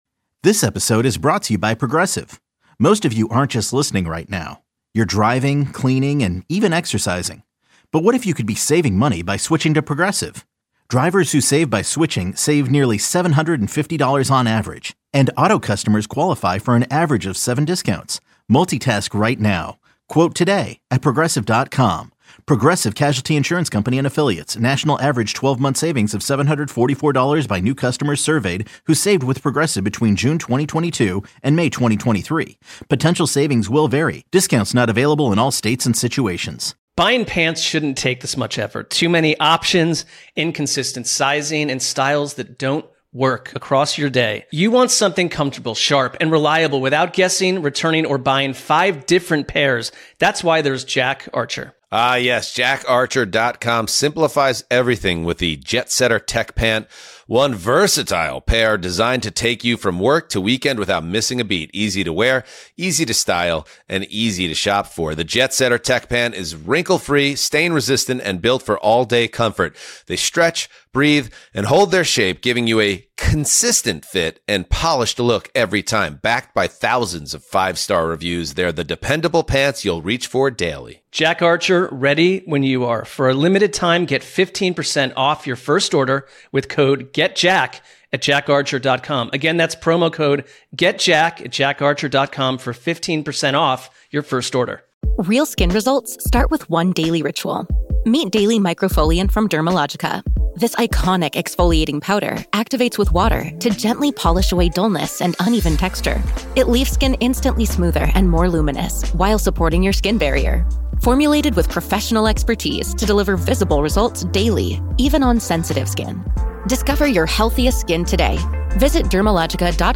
After that, they opened up the phone lines for Score callers to sound off on the MLB lockout that seems inevitable.